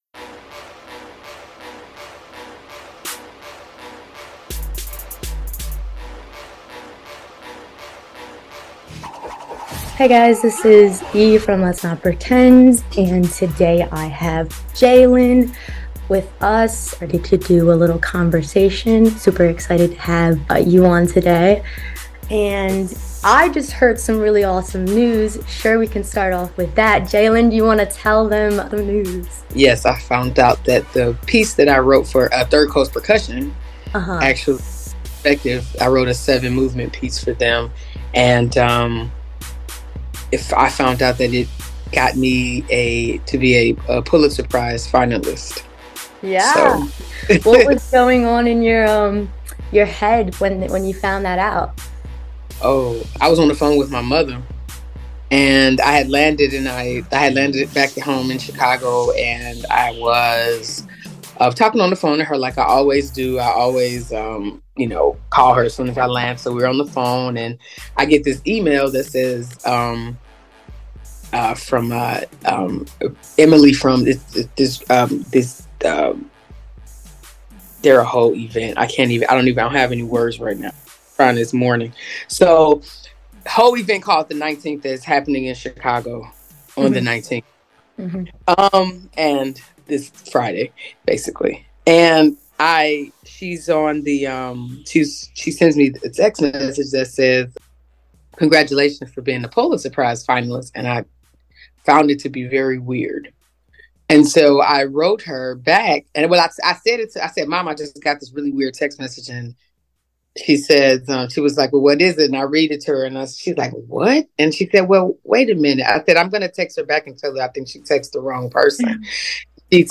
Be a guest on this podcast Language: en Genres: Entertainment News , Music , Music Interviews , News Contact email: Get it Feed URL: Get it iTunes ID: Get it Get all podcast data Listen Now... Jlin (Jerrilynn Patton) on growth as her signature